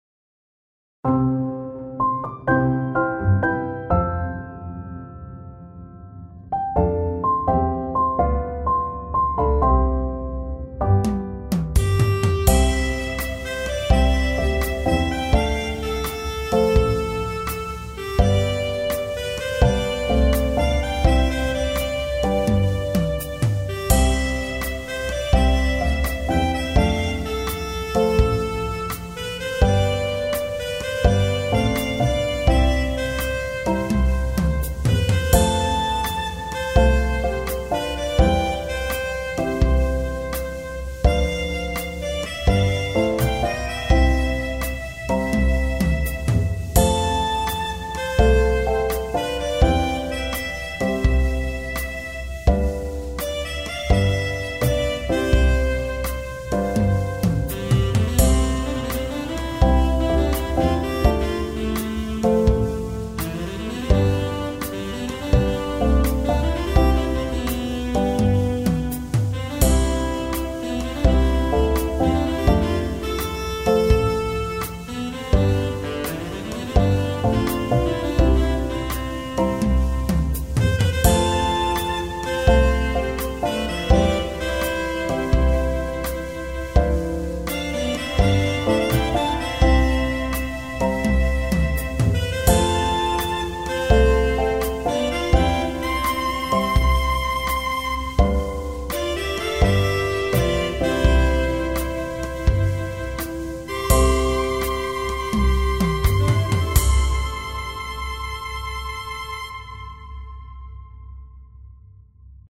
ジャズ明るい穏やか